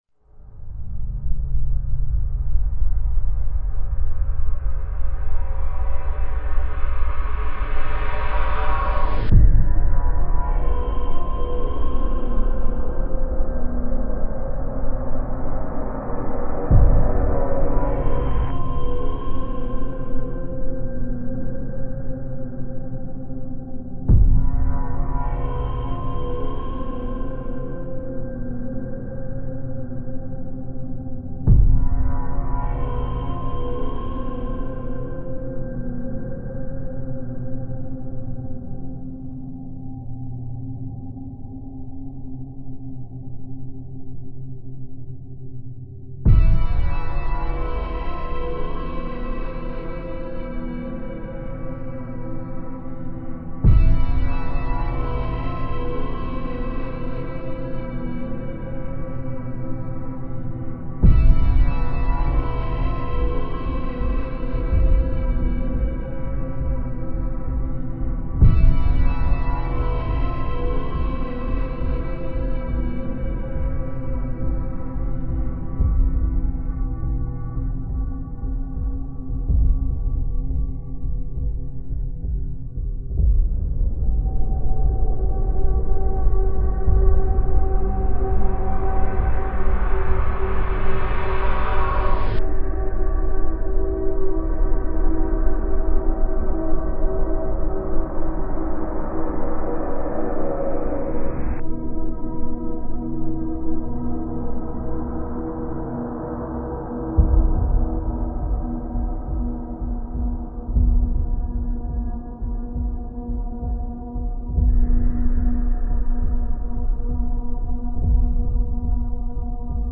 Space Ambo